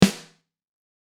there are two versions, on is dry and the other one is with a room sample blended in for extra KSSSCHHHH.
It's a Sensitone Elite Custom Alloy snare with a powerstroke head btw.